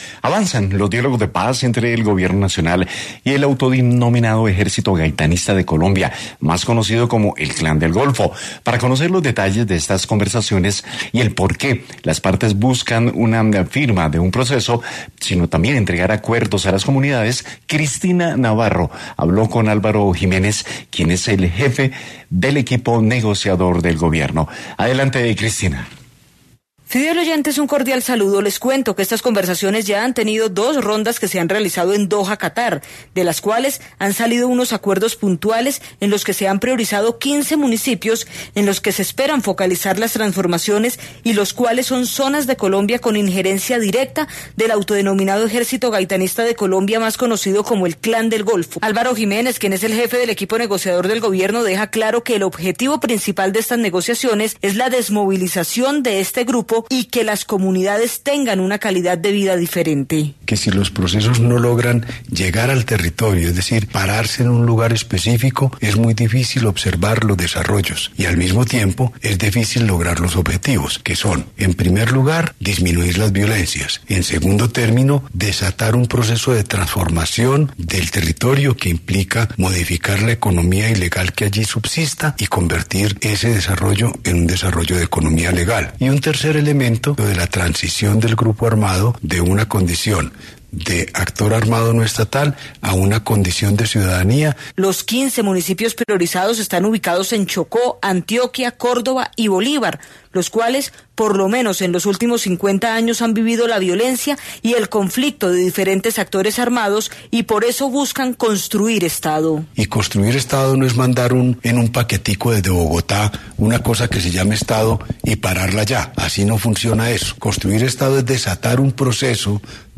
Este proceso también tiene una particularidad según nos explicó en este diálogo con Caracol Radio, Álvaro Jiménez, el jefe del equipo negociador del Gobierno en las conversaciones con el autodenominado Ejército Gaitanista de Colombia, más conocido como el Clan del Golfo, y que cuenta con un grupo de países no como es la costumbre en los procesos de paz que son acompañantes o garantes, aquí son mediadores.